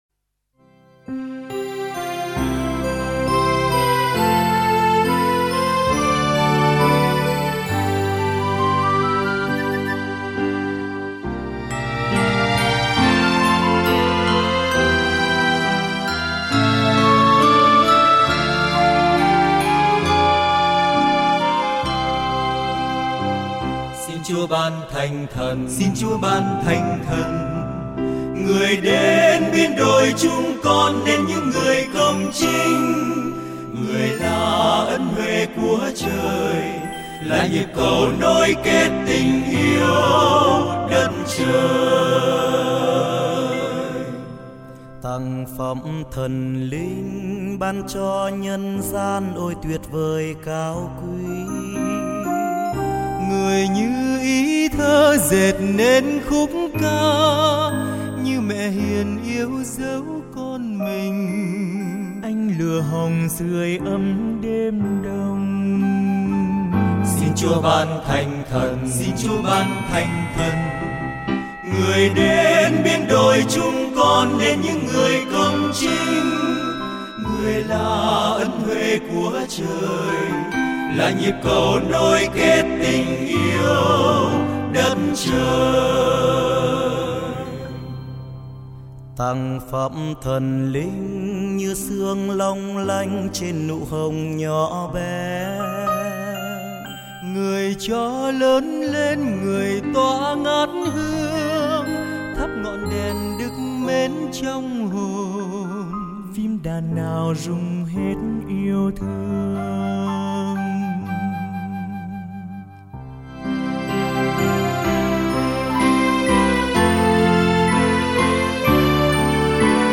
ca khúc bày tỏ lòng tôn vinh Ba Ngôi Thiên Chúa